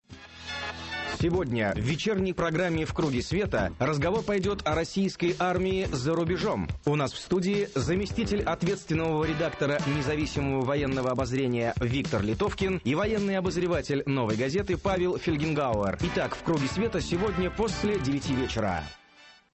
Аудио: анонс –